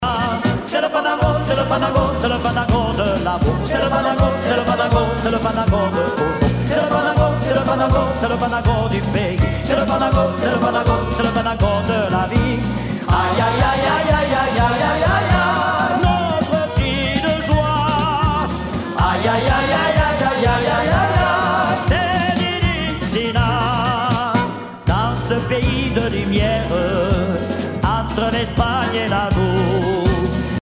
Pays Basque
Pièce musicale éditée